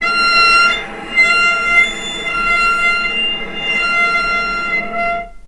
healing-soundscapes/Sound Banks/HSS_OP_Pack/Strings/cello/sul-ponticello/vc_sp-F5-mf.AIF at cc6ab30615e60d4e43e538d957f445ea33b7fdfc
vc_sp-F5-mf.AIF